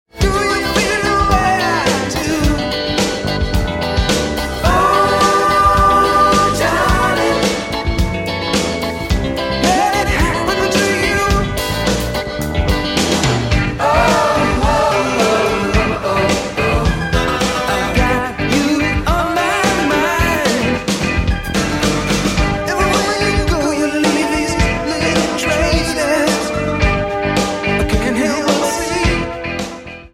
guitar, keyboards, percussion, vocals
bass
drums